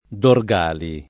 [ dor g# li ]